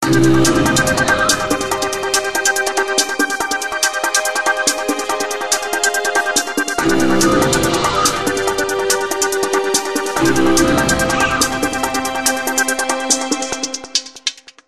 Kategorien Wecktöne